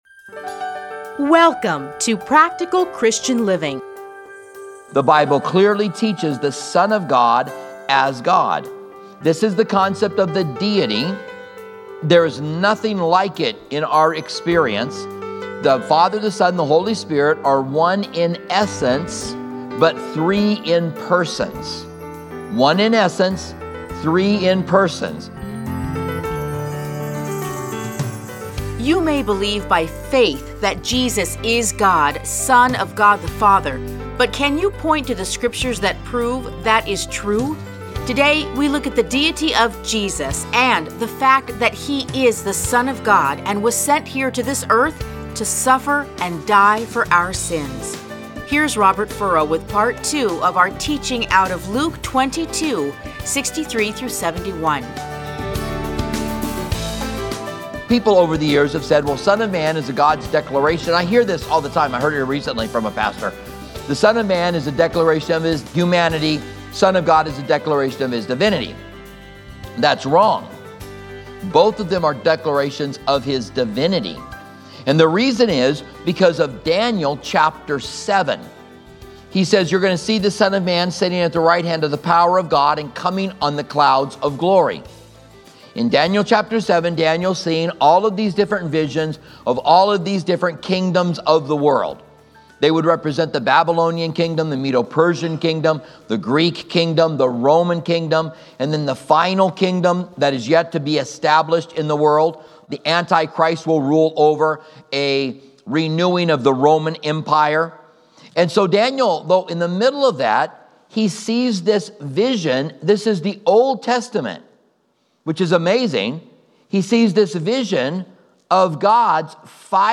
Listen to a teaching from Luke 22:63-71.